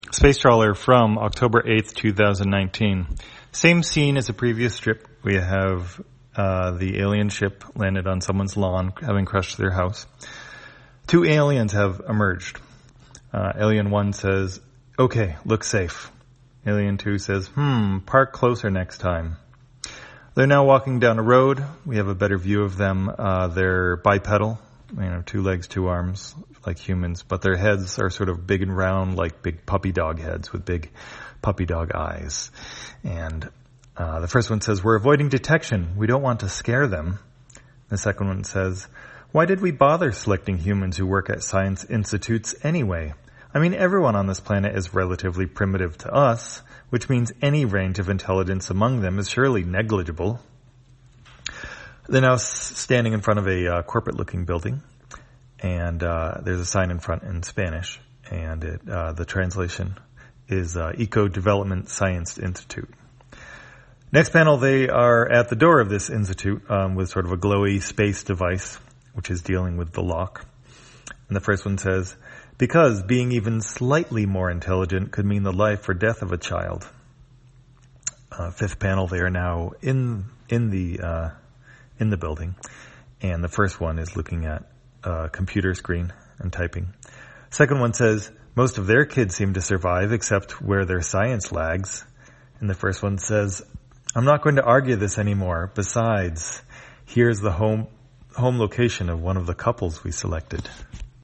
Spacetrawler, audio version For the blind or visually impaired, October 8, 2019.